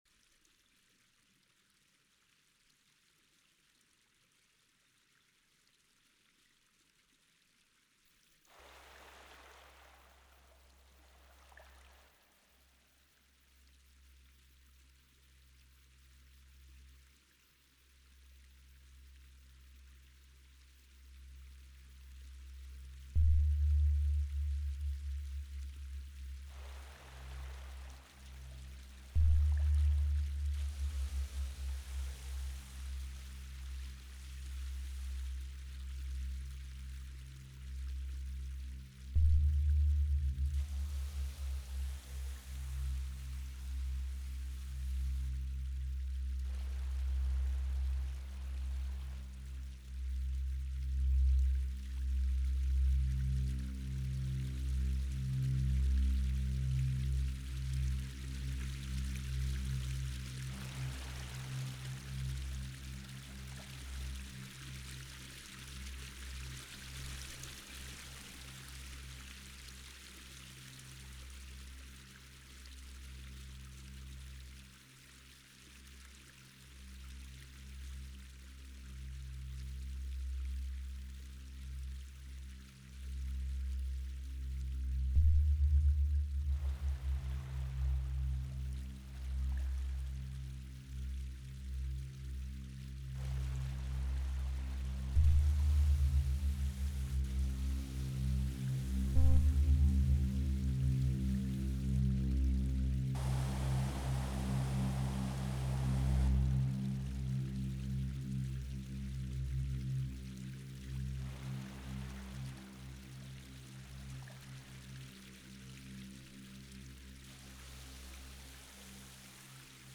Eine grundsätzliche neue Art der Darstellung dieser Änderungssignale von Umweltdaten durch den Klimawandel wäre die algorithmische Aufbereitung der Daten in akustische Signale.
1) Demo Sound 2023